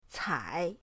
cai3.mp3